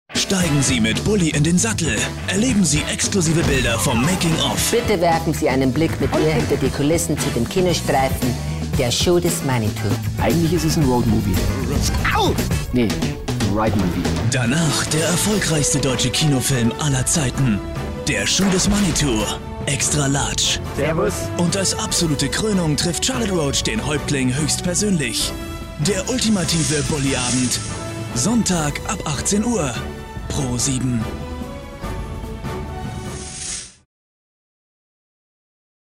deutscher Sprecher
Kein Dialekt
Sprechprobe: Werbung (Muttersprache):
voice over artist german